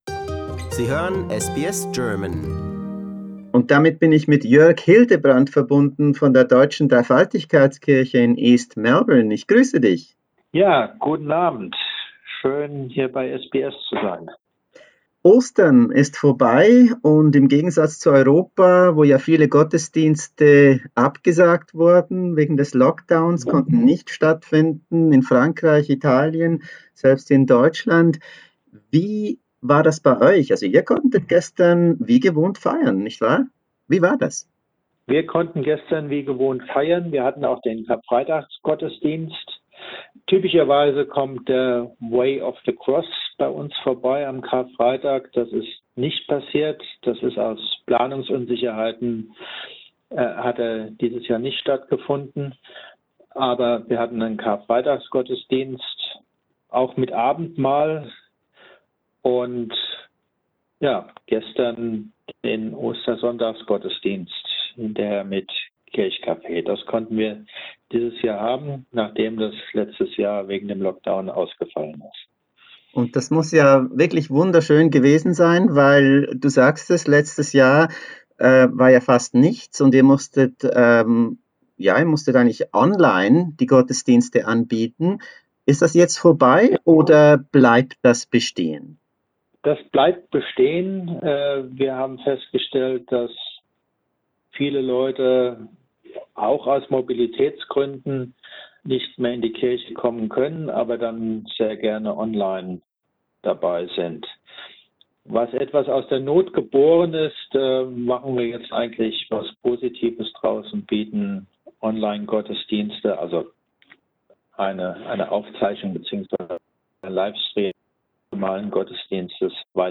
in einem Interview zum Ostermontag